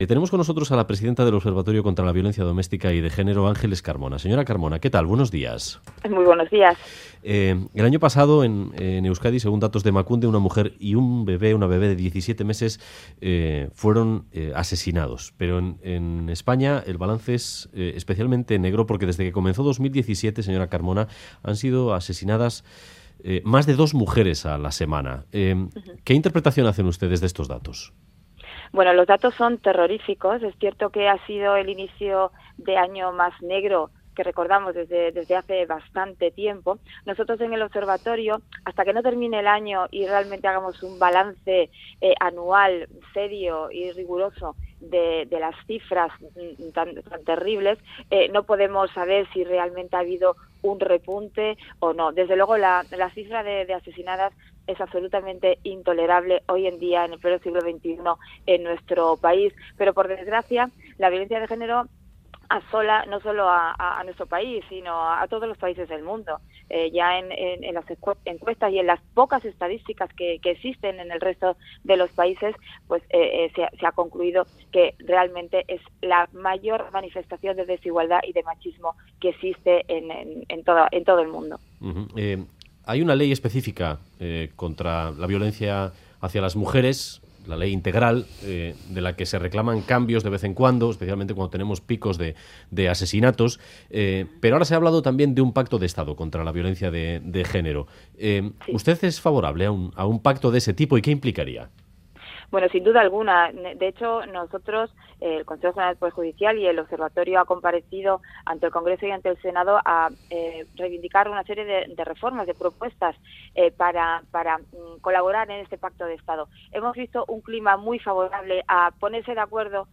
Entrevista a Ángeles Carmona: 'Es necesario un pacto de Estado'